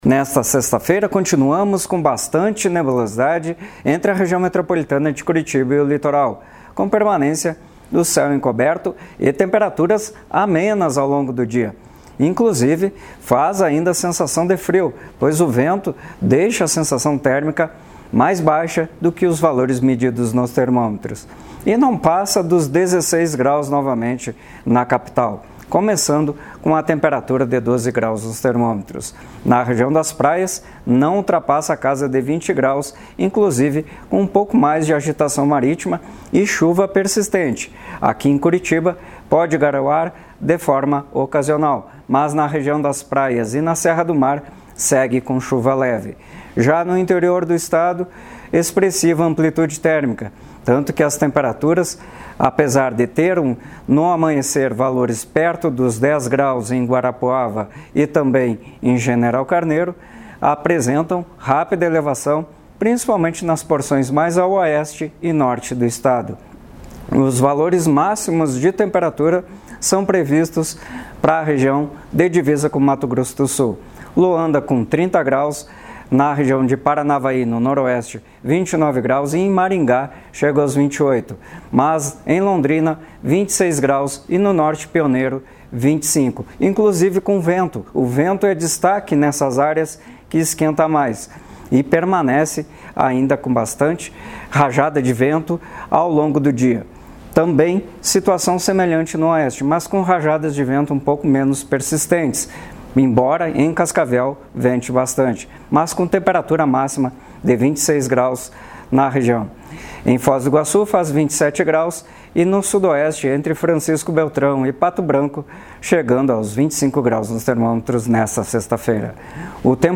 Ouça a previsão em detalhes com o meteorologista do Simepar